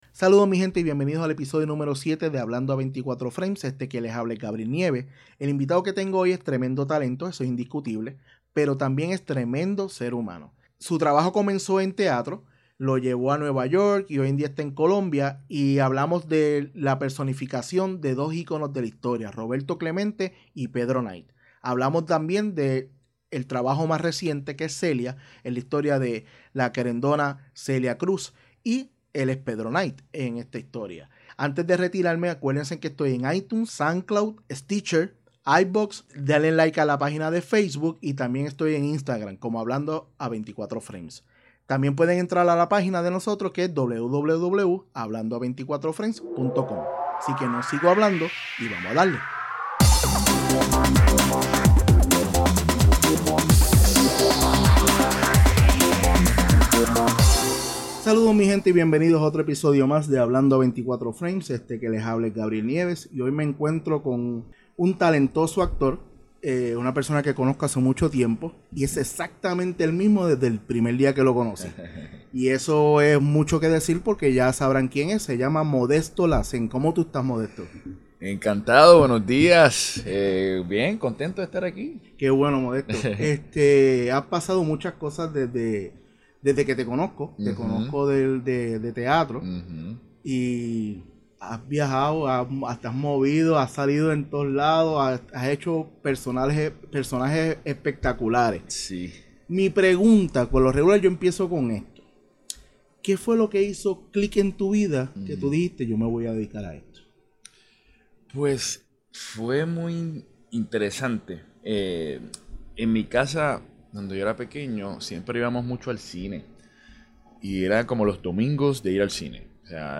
Esta bien buena esta conversación y son de esos momentos en que te sientes orgulloso de que alguien que conoces y se a matado trabajando lo logra y en grande.